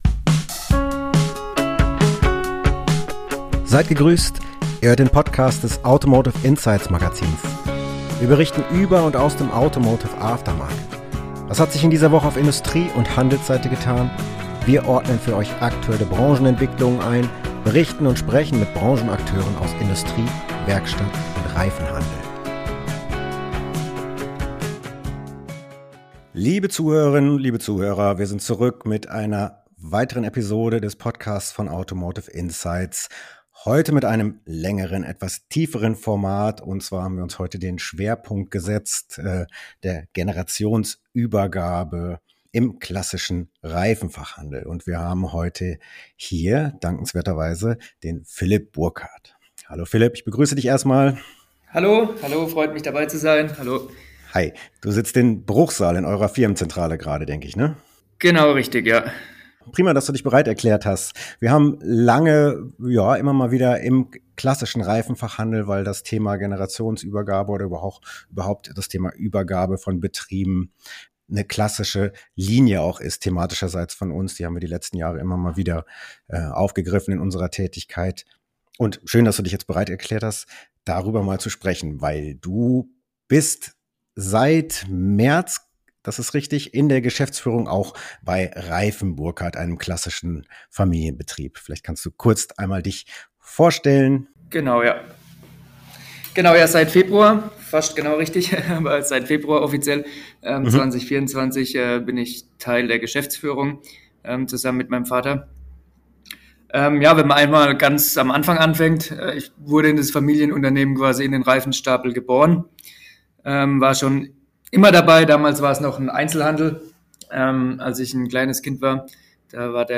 Interview-Episode